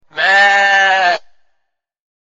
Farm Sounds
Bleating-sheep.mp3